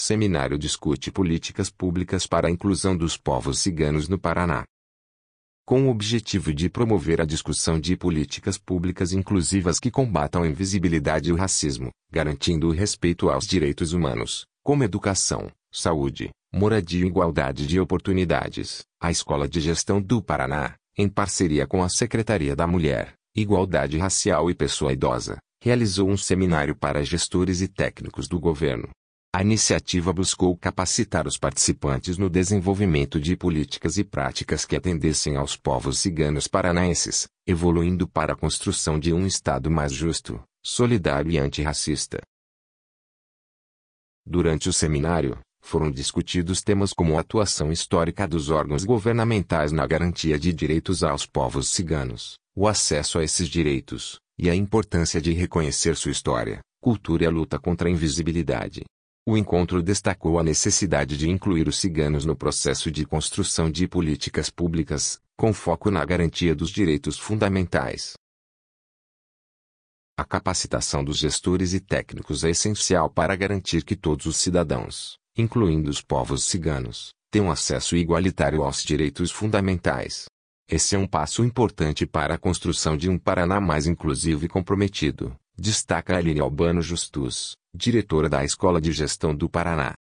audionoticia_seminario_povos_ciganos.mp3